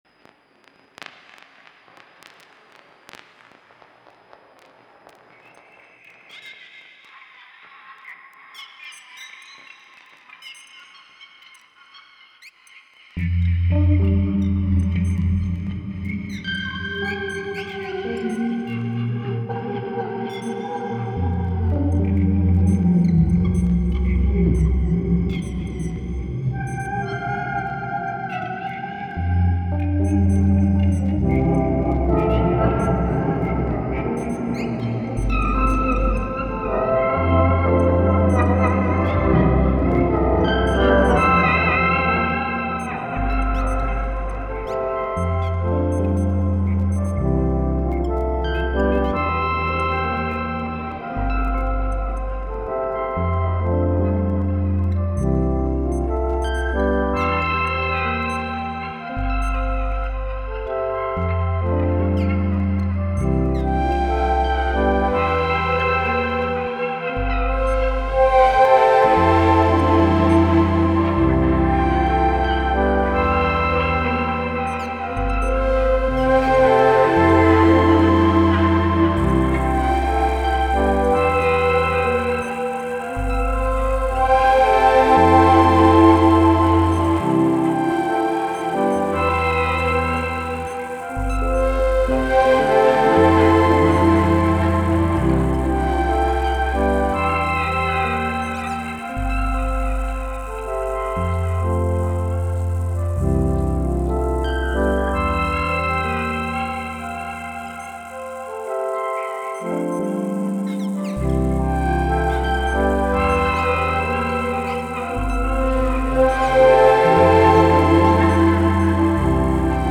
Last days of summer, weird mood kicking.